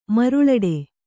♪ maruḷaḍe